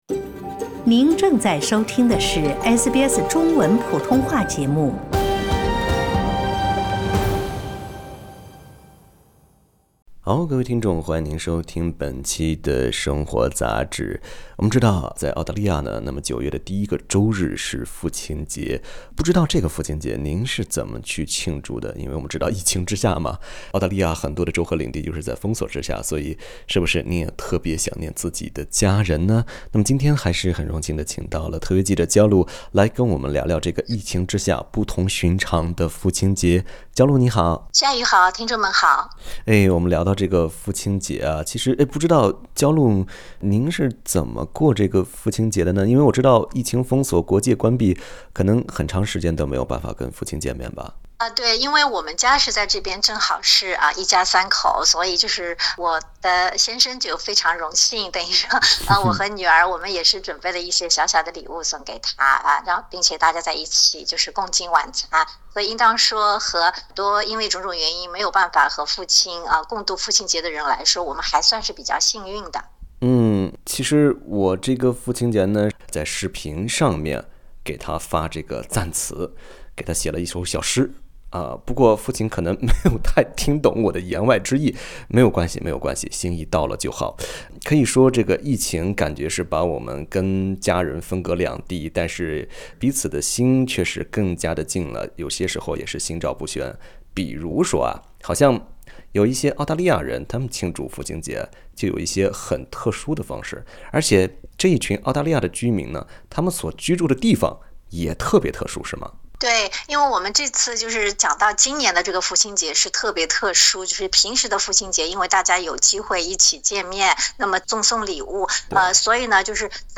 Source: AAP SBS 普通话电台 View Podcast Series Follow and Subscribe Apple Podcasts YouTube Spotify Download (21.21MB) Download the SBS Audio app Available on iOS and Android 9月5日父亲节，在昆州与新州边界处，当地的居民在警方的监督下与亲人团聚。